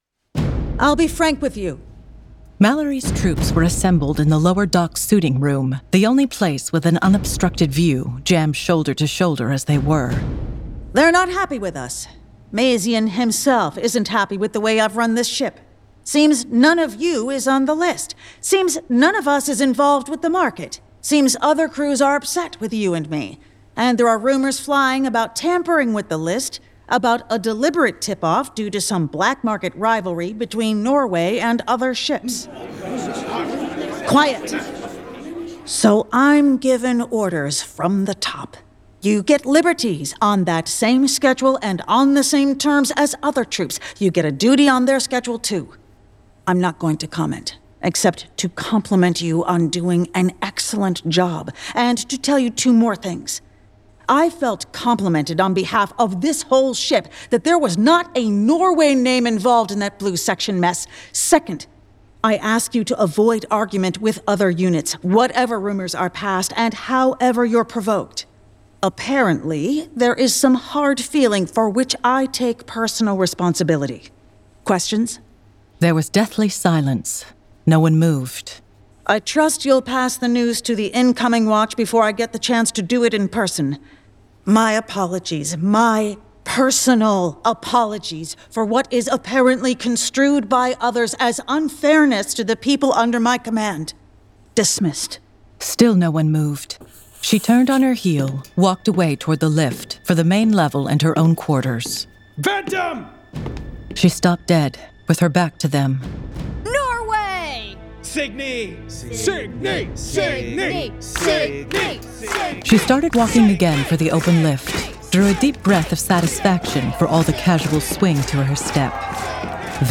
Full Cast. Cinematic Music. Sound Effects.
[Dramatized Adaptation]
The Hugo Award-winning classic sci-fi novel, brought to life with a full cast, sound effects and cinematic music!